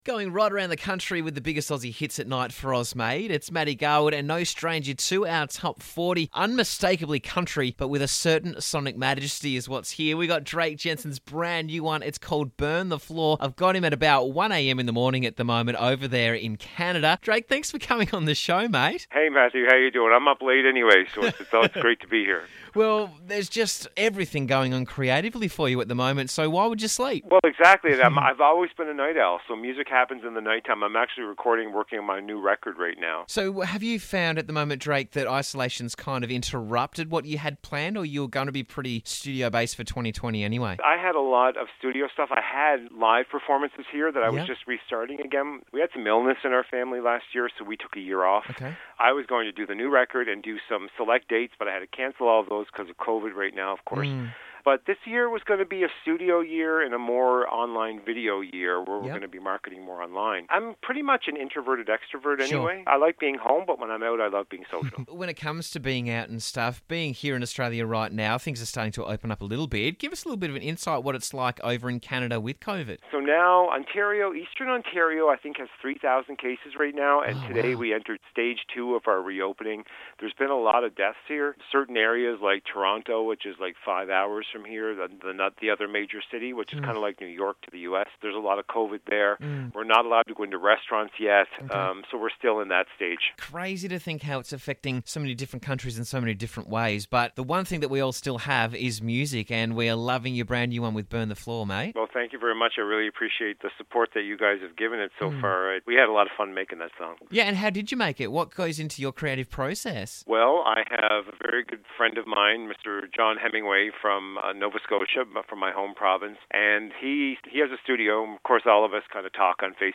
“Burn The Floor” is unmistakably country, but with a certain sonic majest
“Burn The Floor”, is an innovative, modern country tune borne out of the